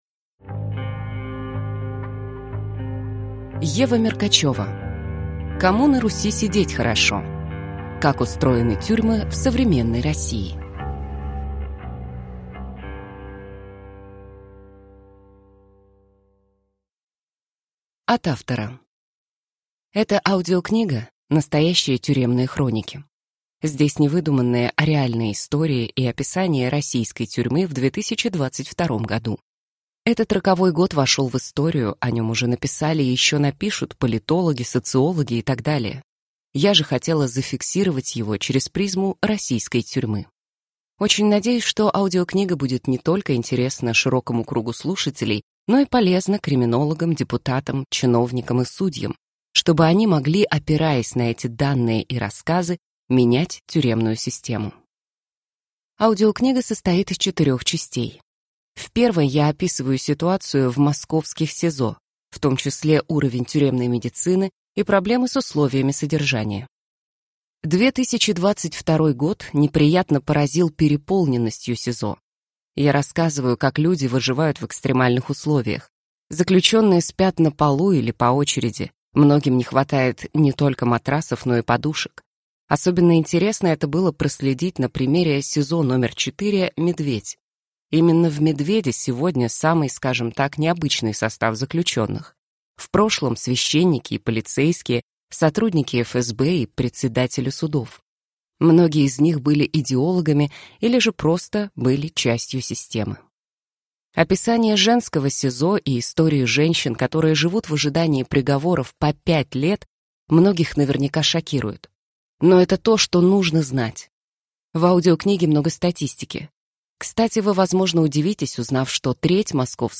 Аудиокнига Кому на Руси сидеть хорошо? Как устроены тюрьмы в современной России | Библиотека аудиокниг